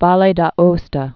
(välā dä-ōstə, -stä)